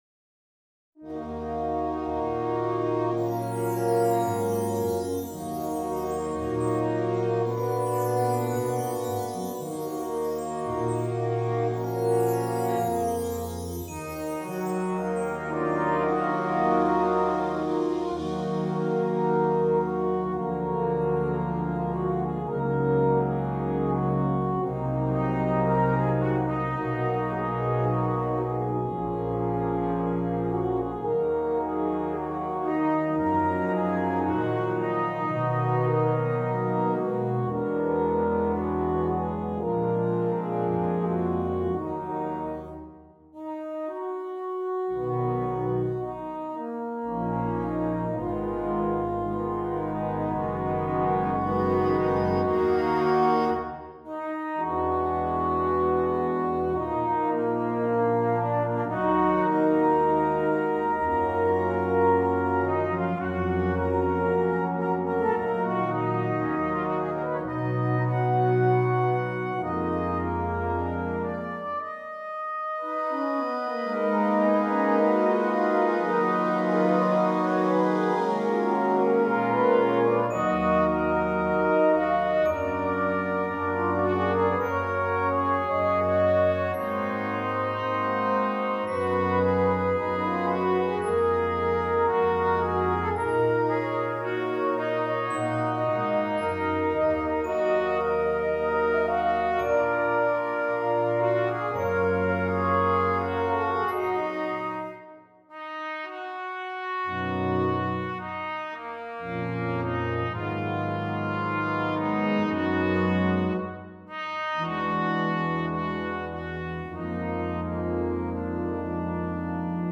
Brass Band
Traditional
as a trumpet or flugel horn solo with band